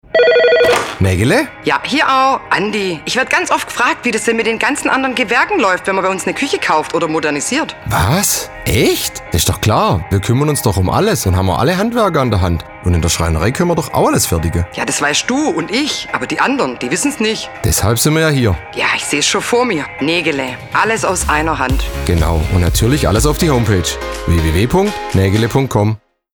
Radiowerbung: Alles aus einer Hand bei Negele
Presse-Radiospot-alles-aus-einer-Hand.mp3